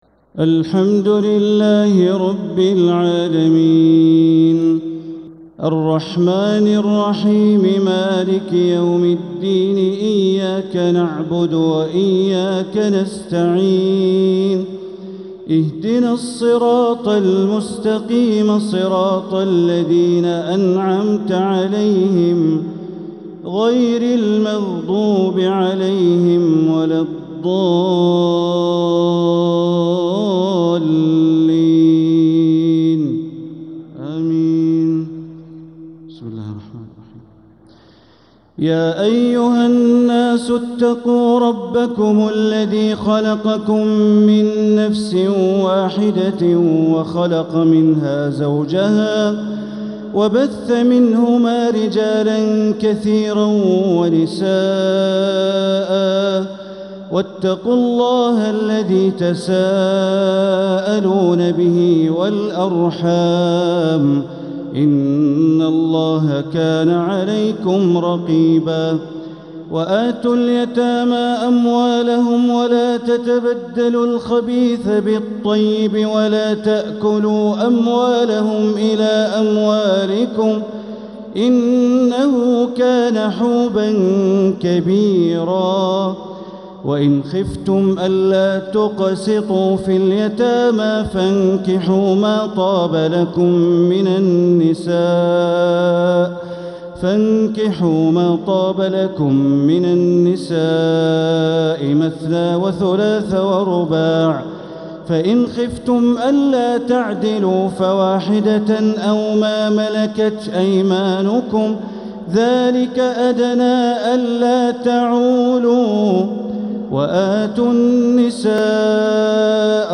Taraweeh 6th night Ramadan 1446H Surat An-Nisaa > Taraweeh Ramadan 1446H > Taraweeh - Bandar Baleela Recitations